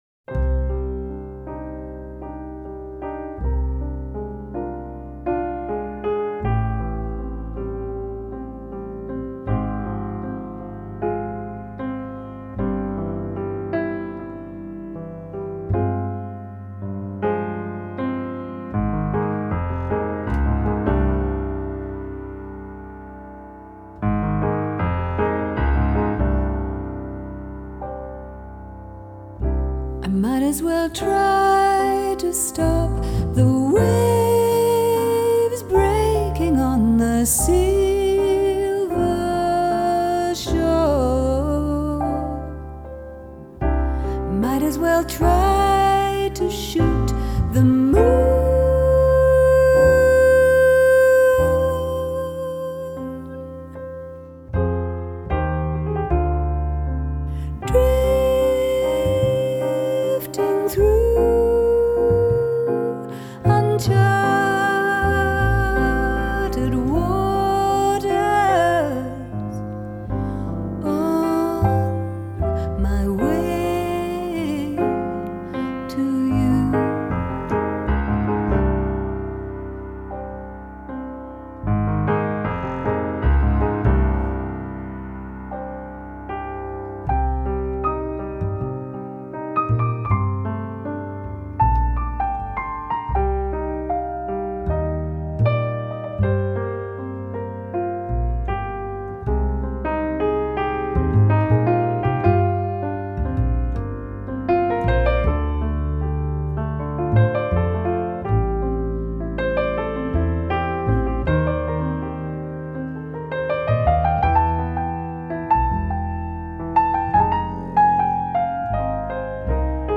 An uplifting auditory experience.